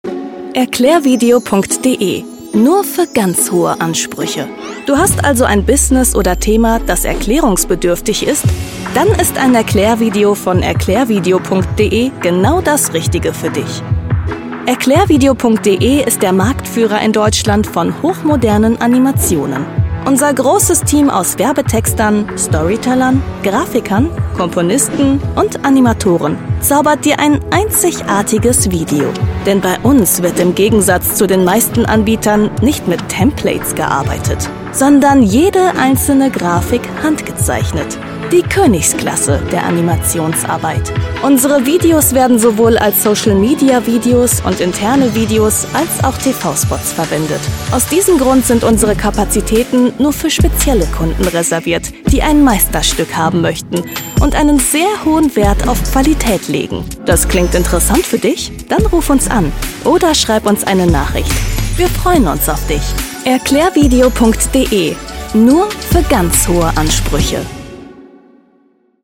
Professionelle Sprecherin